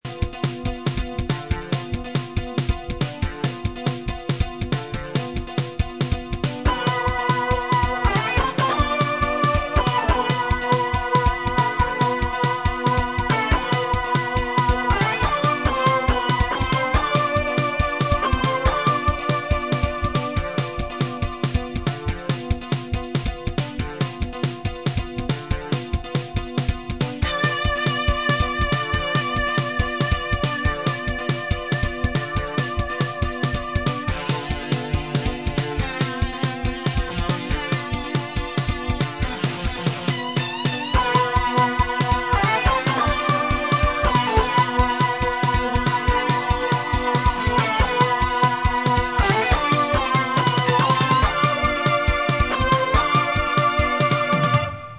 シューティングゲーム風BGMその7
試聴環境：Ｘ−６８０３０ ＋ ＳＣ-８８ＶＬ
ちょっと和風なテイストの曲を作ろうとしたら、ただ変なだけの曲になってしまいました。
ギターのギョインギョインいってるのが、かなりわざとらしい感じも有りますが、どうなんでしょう。